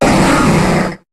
Cri de Foretress dans Pokémon HOME.